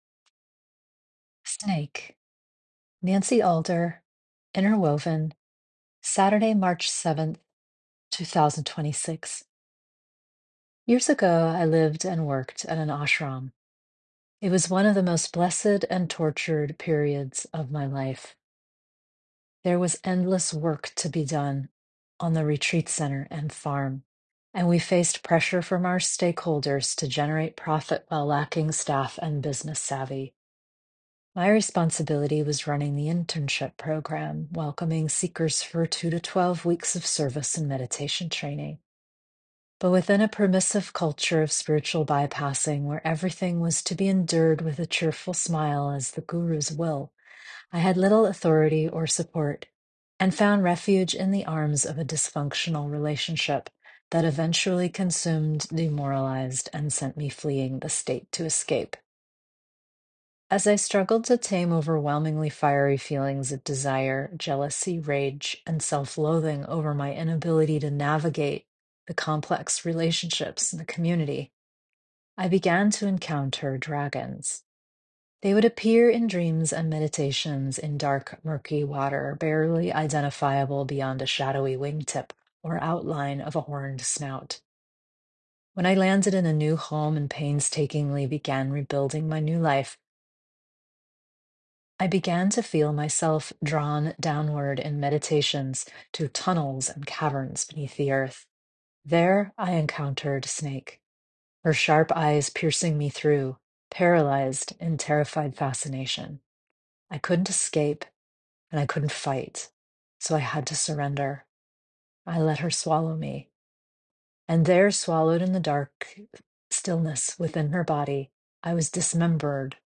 Enjoy this 6 1/2 minute read or let me read it to you here